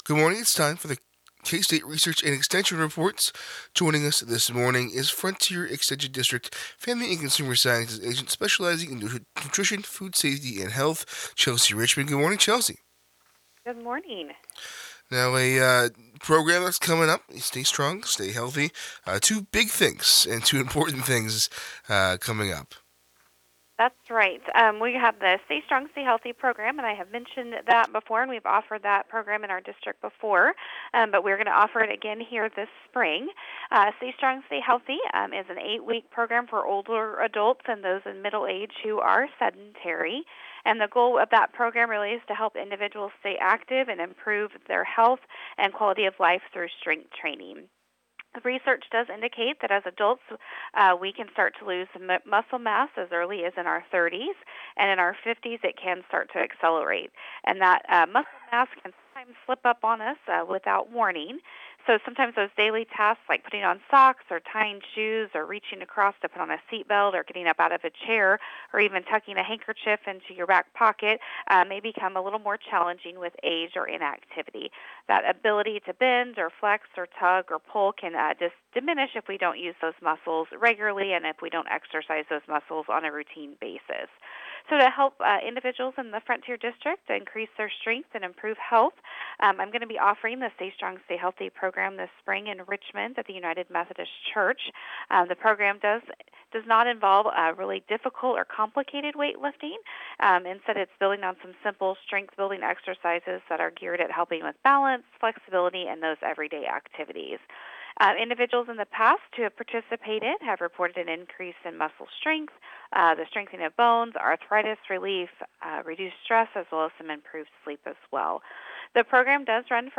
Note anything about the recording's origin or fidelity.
KOFO Radio 2026 Recordings – Local Broadcast Audio Archive